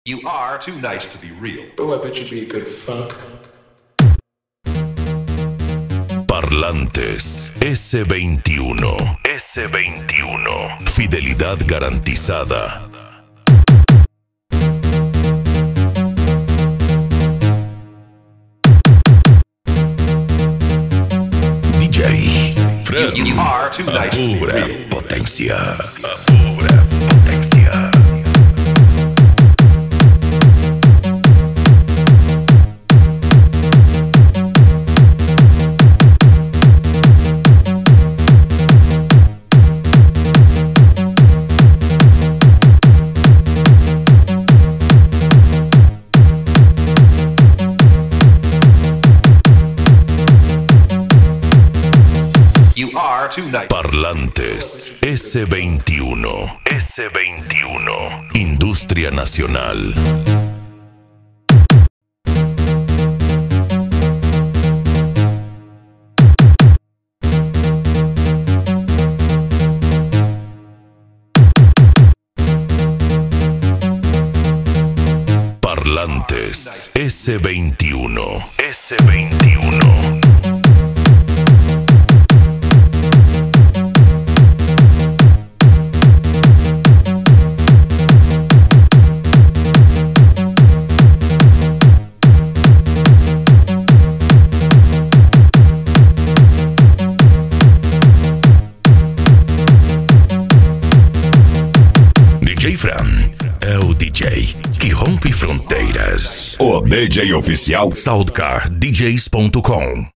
Eletronica
PANCADÃO
Psy Trance
Remix